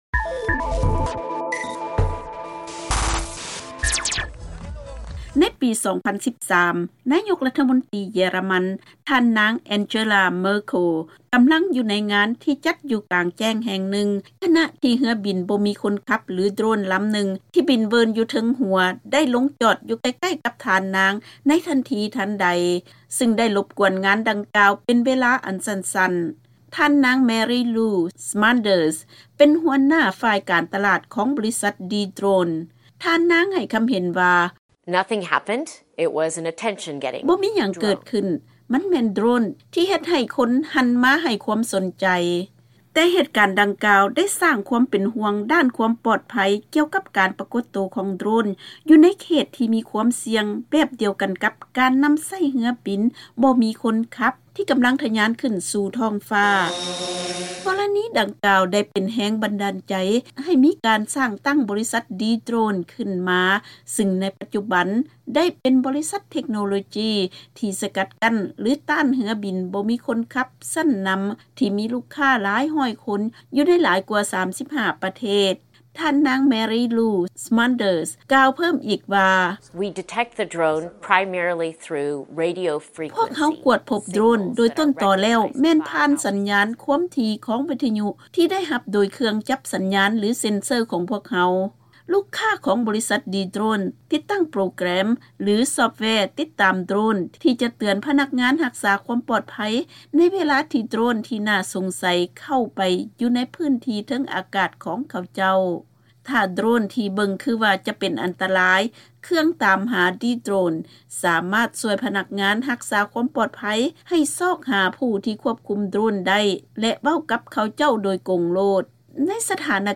ເຊີນຟັງລາຍງານເລື້ອງ ເທັກໂນໂລຈີໃນການຕໍ່ຕ້ານເຮືອບິນບໍ່ມີຄົນຂັບ